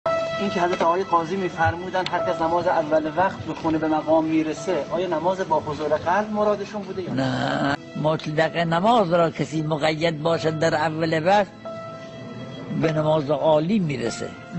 سخنرانی | تاثیر نماز اول وقت در رسیدن به مراتب عالی
بخشی از سخنرانی آیت الله محمد تقی بهجت(ره)